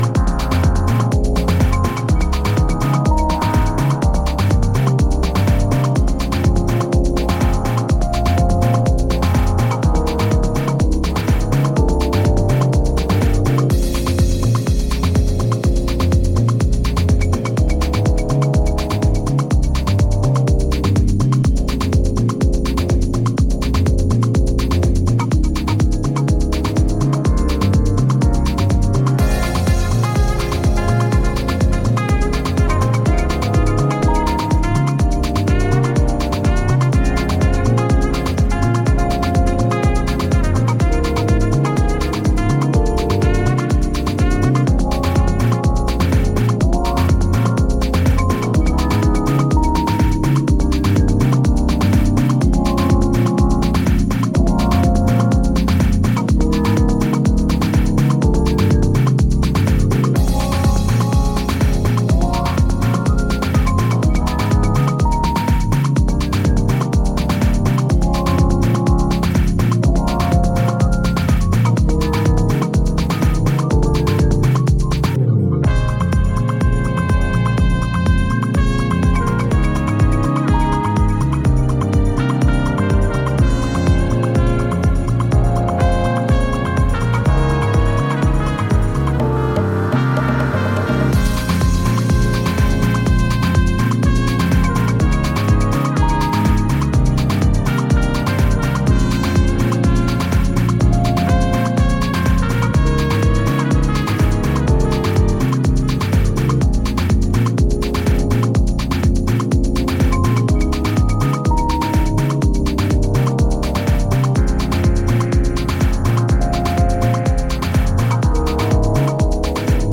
トランペット、フルート、エレピなどのメロディーを配しながら温もりを帯びたジャズファンク・ハウスを繰り広げています。
instrumental mix